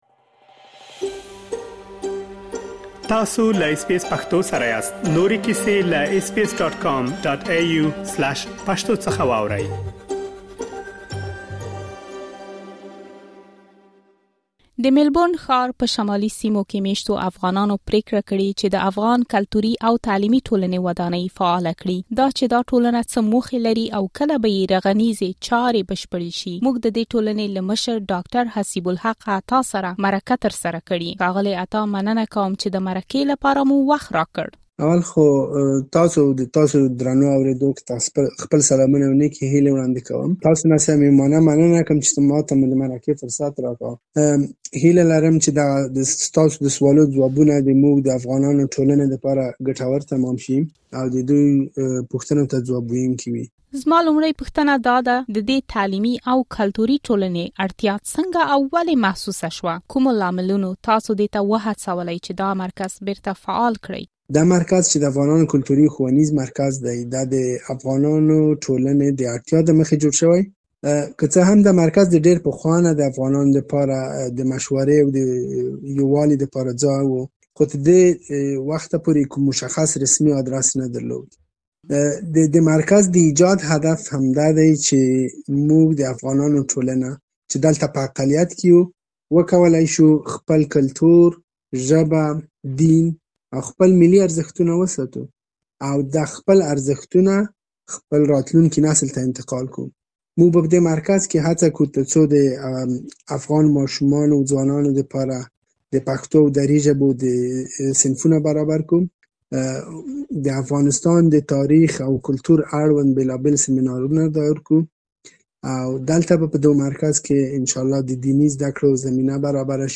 مرکه ترسره کړې.